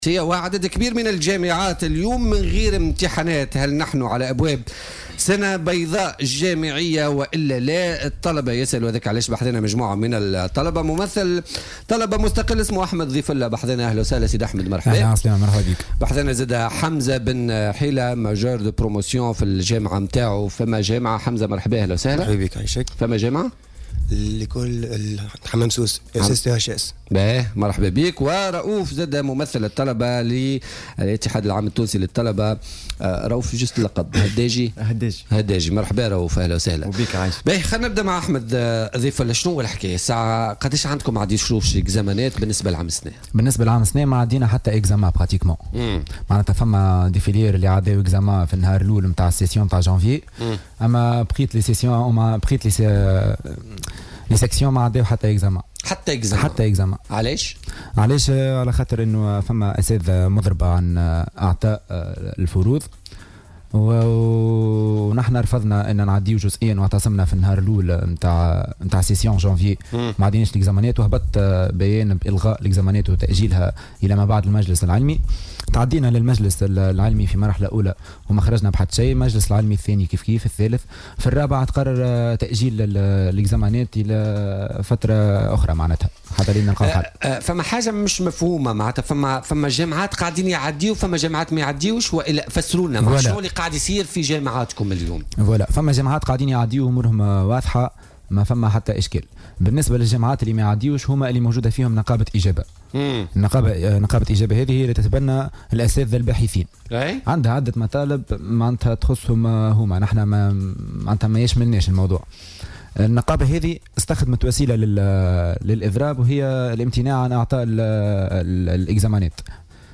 أعرب اليوم الخميس طلبة يمثلون عددا من الجامعات عن تخوفهم من "سنة بيضاء" هذا العام بسبب احتجاجات الأساتذة واضراباتهم.